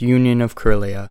How to Pronounce "Union of Krylia"